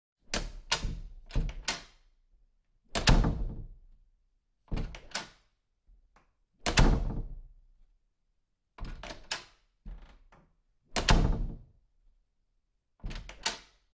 Door Open and Close Sound Effect